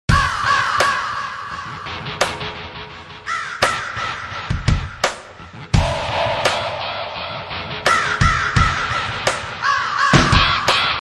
RANDOM BIRD SOUNDS FILL THE ARENA!
entrance music
I mean, really, SQUAWK SQUAWK?